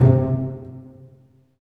STR PIZZ.09R.wav